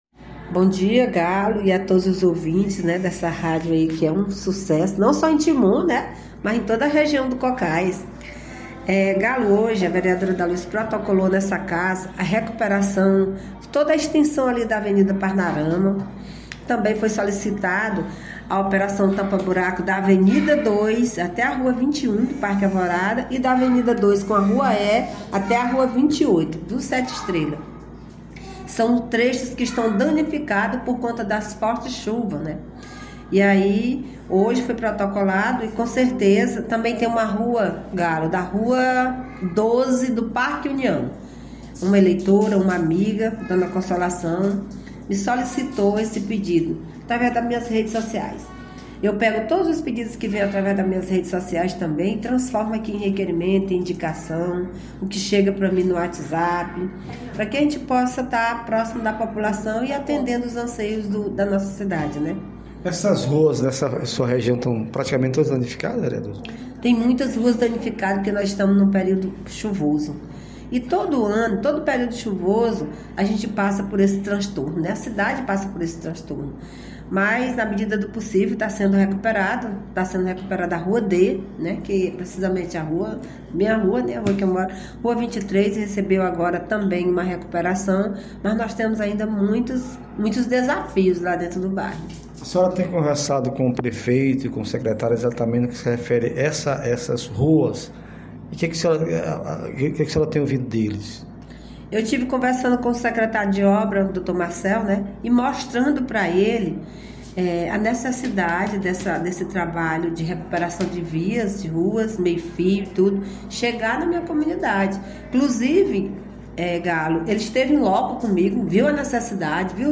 Em entrevista concedida Programa do Galo, a vereadora Da Luz destacou sua luta pela região do Grande Parque Alvorada, e ressaltou a situação de várias ruas daquela região, que devido o periodo chuvoso estão intrafegáveis e necessitando de recuperação.